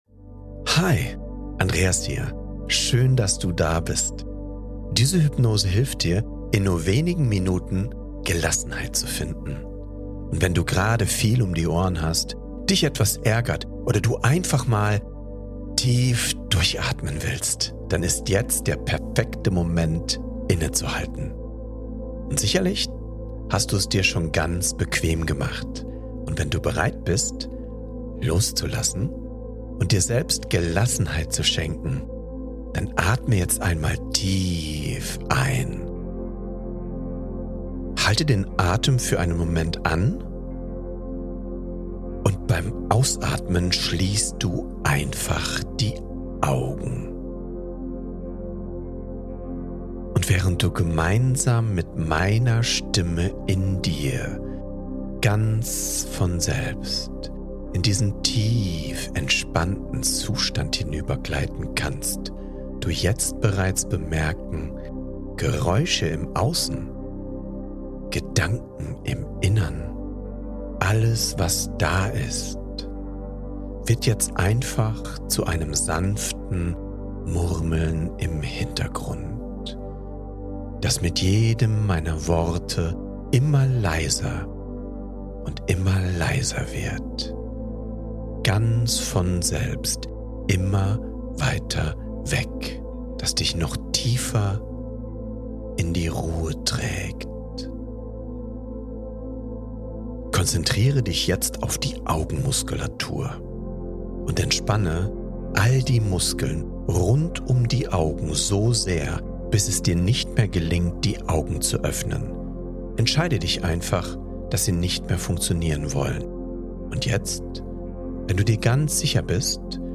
8 Minuten Hypnose, die dir hilft, Stress loszulassen & deine innere Balance wiederzufinden